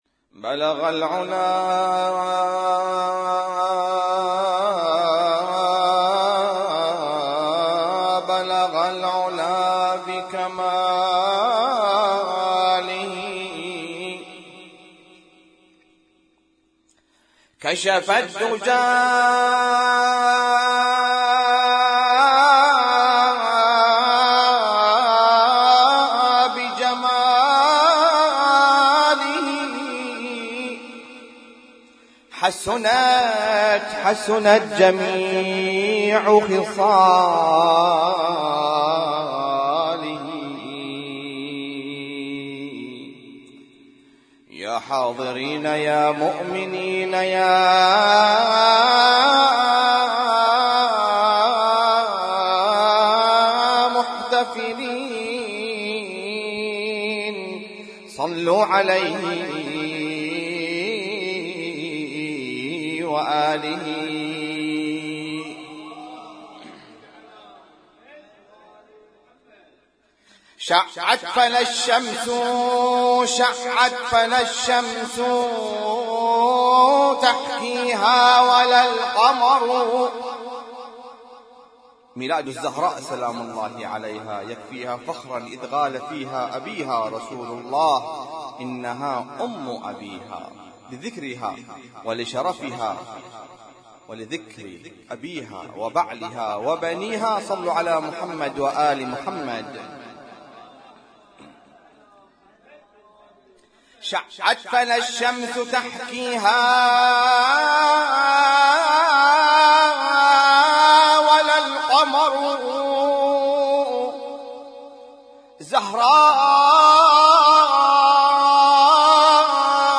اسم التصنيف: المـكتبة الصــوتيه >> المواليد >> المواليد 1437